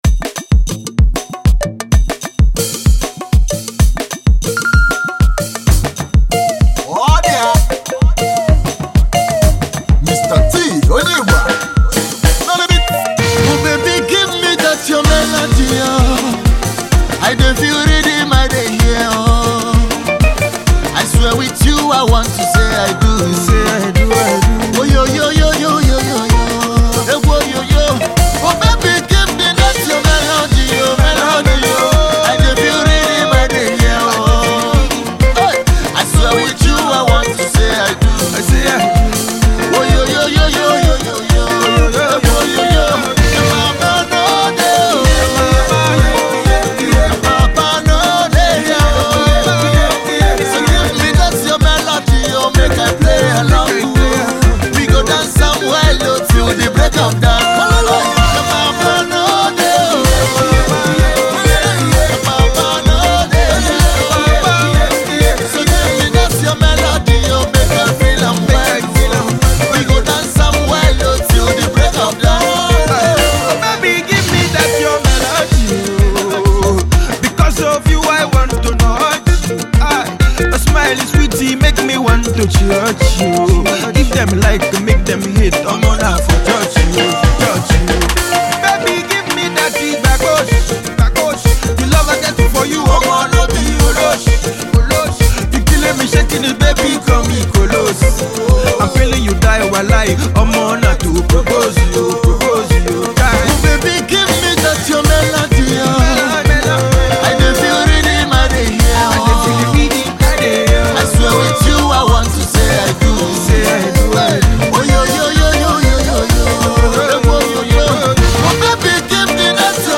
Pop song
Its a Well produced and performed love song